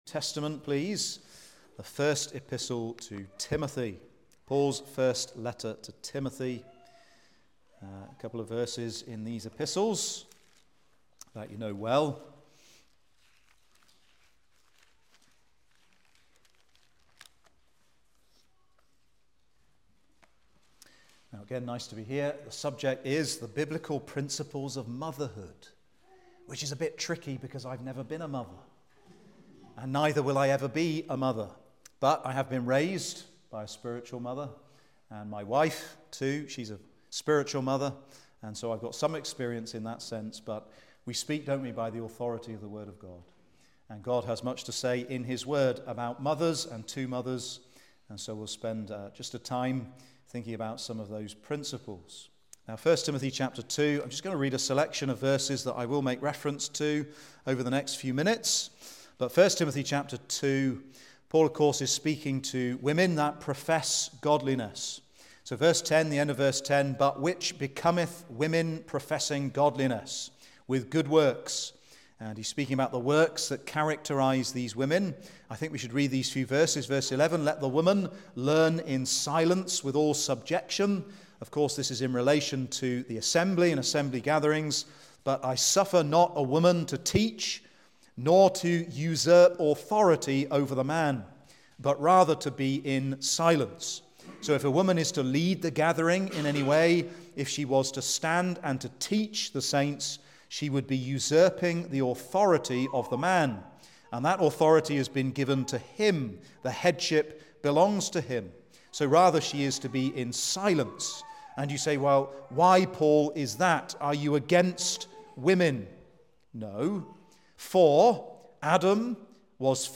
preaches on the Biblical pattern of motherhood. A rich vein of truth drawn from 1 Timothy ch 2, Titus ch 2 and the virtuous woman of Proverbs ch 31.